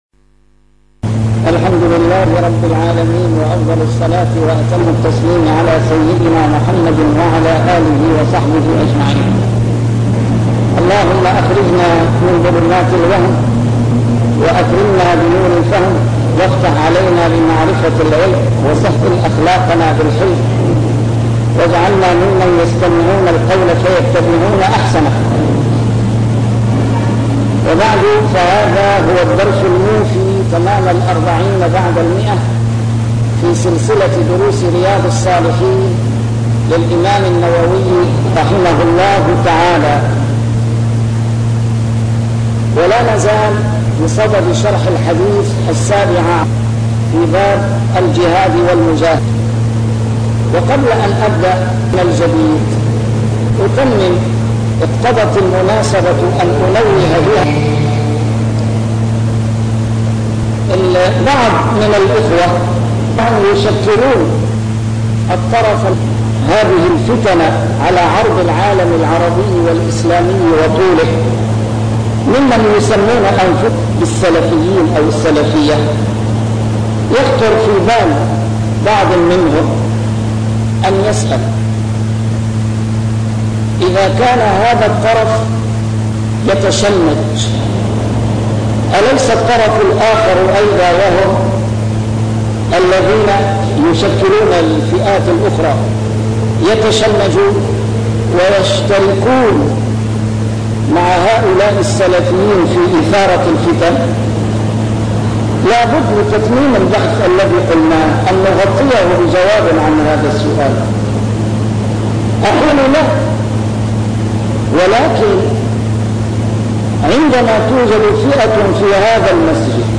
نسيم الشام › A MARTYR SCHOLAR: IMAM MUHAMMAD SAEED RAMADAN AL-BOUTI - الدروس العلمية - شرح كتاب رياض الصالحين - 140- شرح رياض الصالحين: المجاهدة